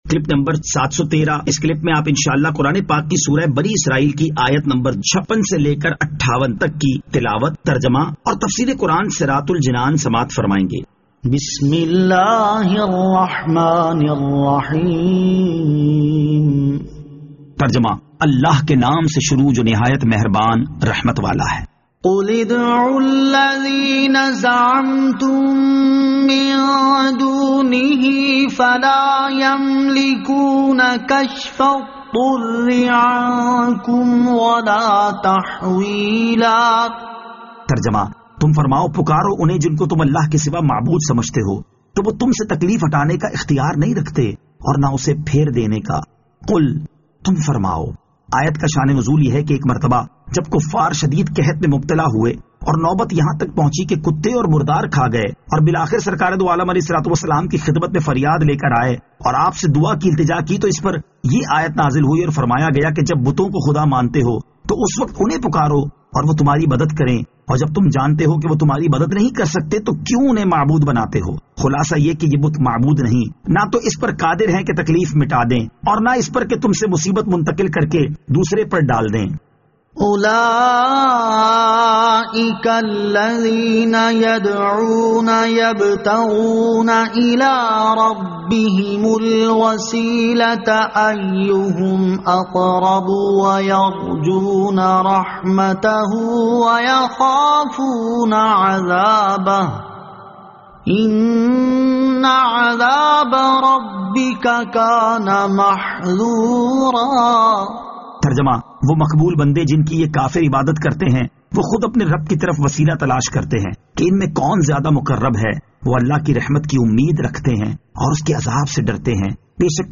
Surah Al-Isra Ayat 56 To 58 Tilawat , Tarjama , Tafseer